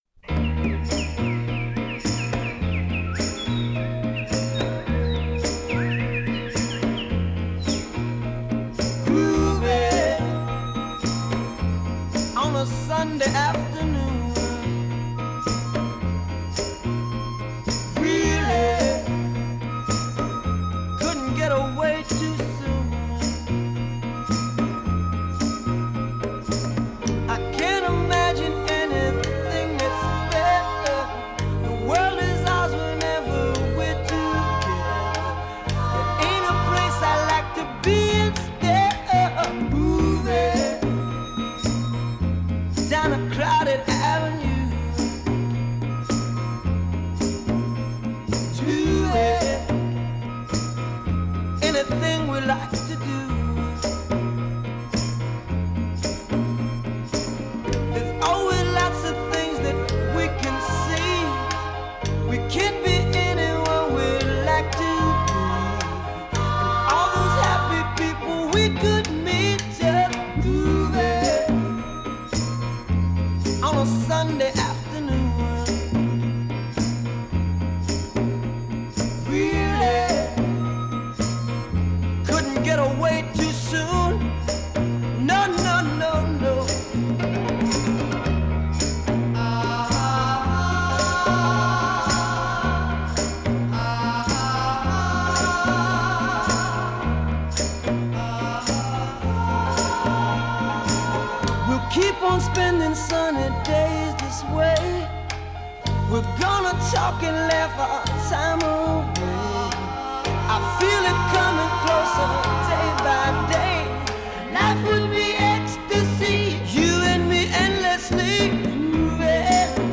Transferred from high-res 24/192.